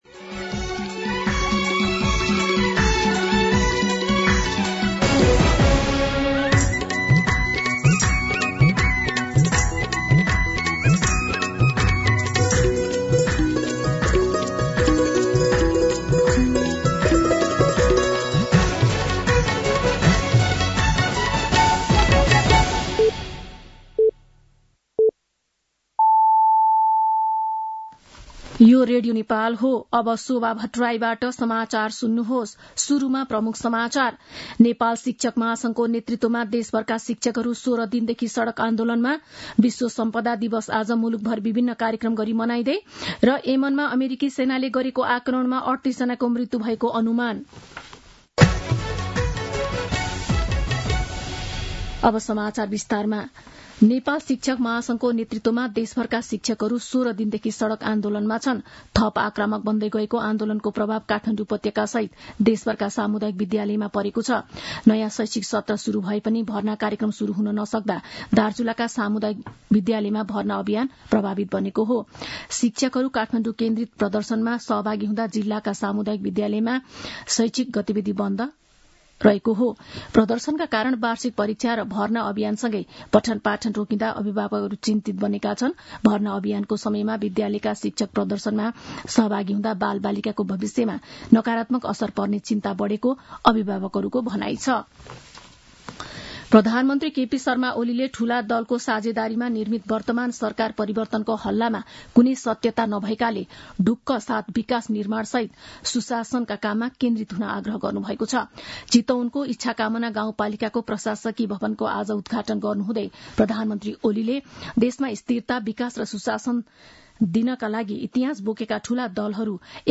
दिउँसो ३ बजेको नेपाली समाचार : ५ वैशाख , २०८२
3-pm-Nepali-news.mp3